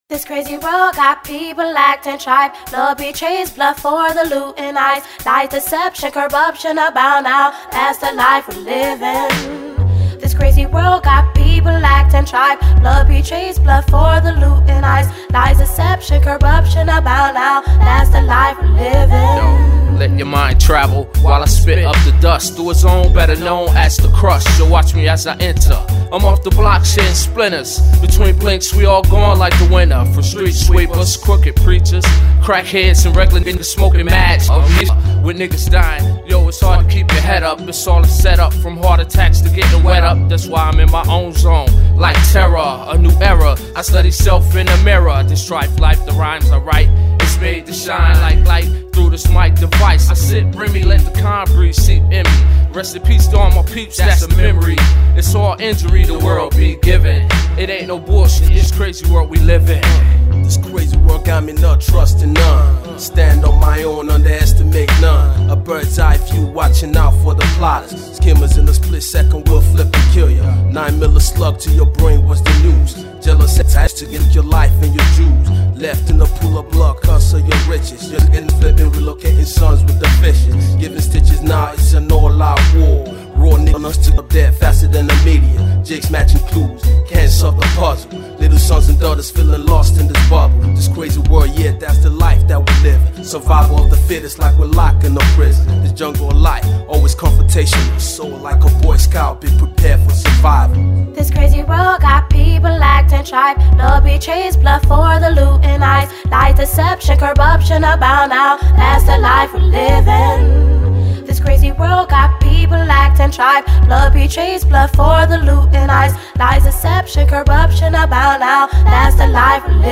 Rap
Hip-hop